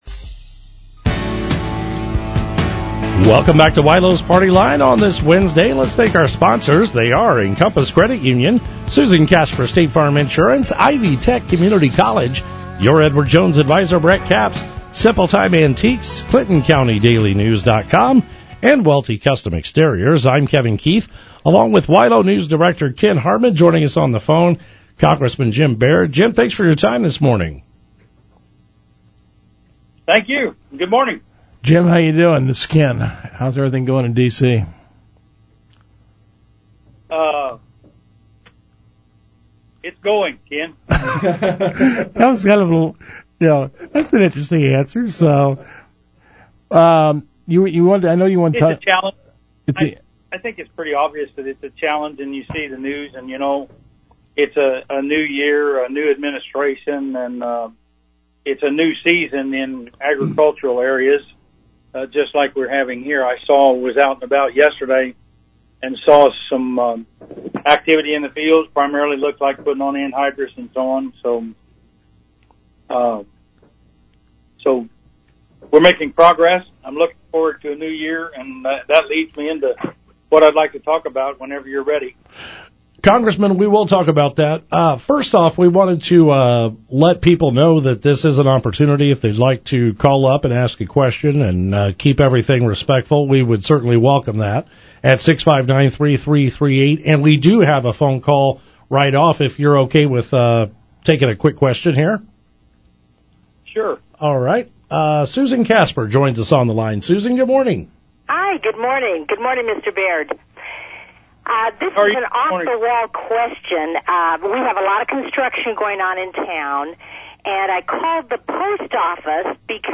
Hear the 25 minute conversation below: